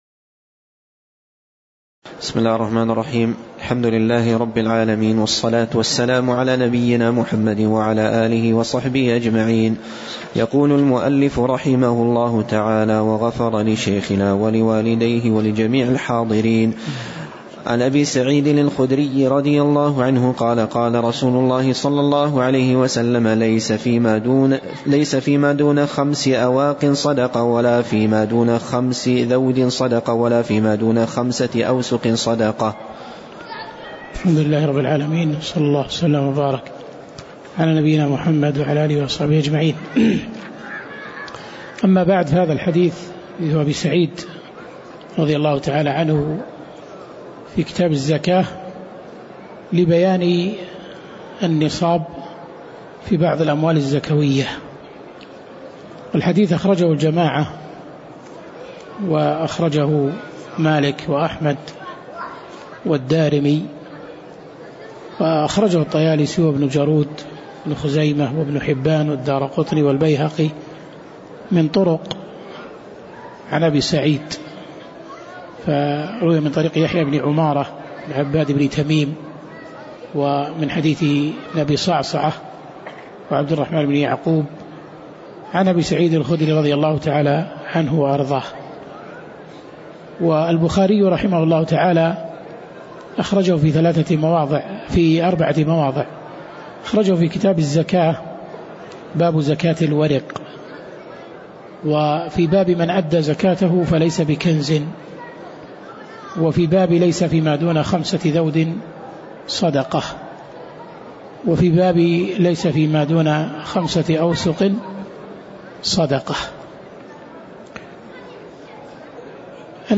تاريخ النشر ١٥ جمادى الأولى ١٤٣٨ هـ المكان: المسجد النبوي الشيخ